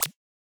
generic-select.wav